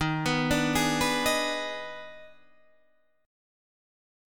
D#M7sus4#5 chord